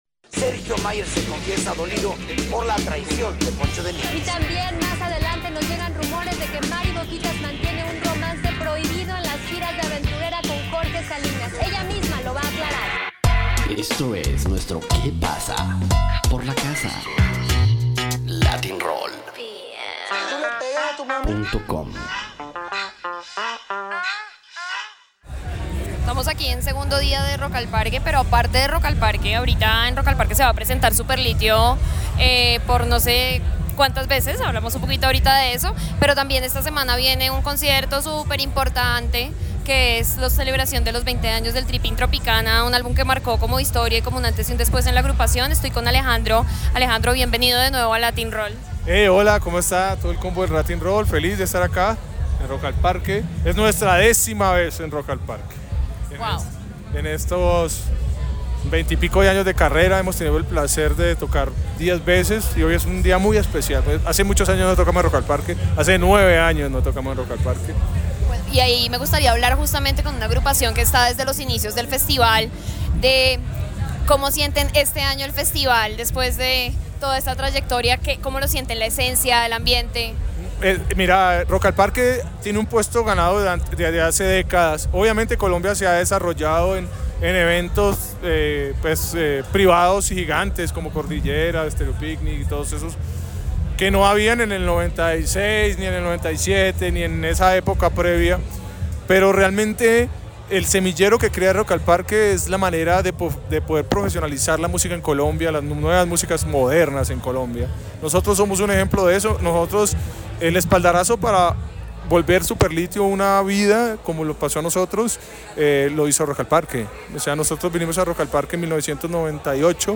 Hablamos en Rock al Parque con la agrupación colombiana sobre este festival público y también sobre la celebración de los 20 años de su emblemático álbum, un clásico del rock colombiano.